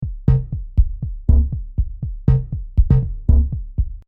声道立体声